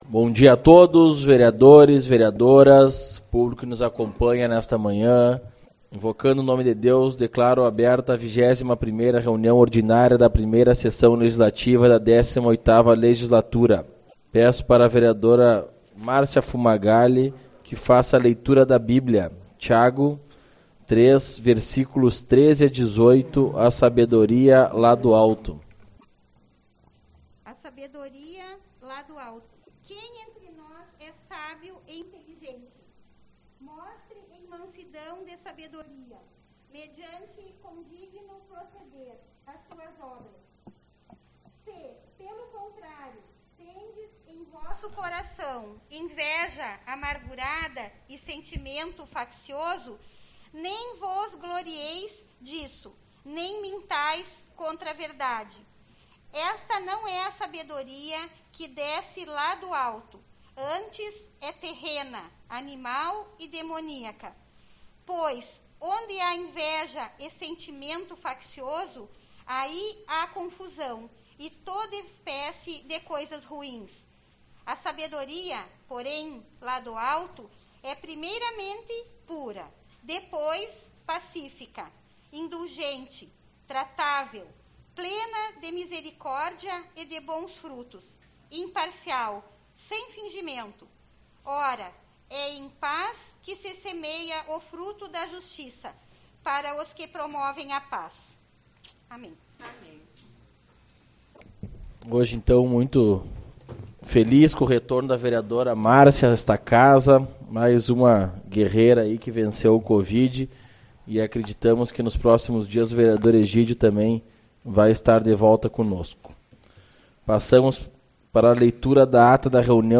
13/04 - Reunião Ordinária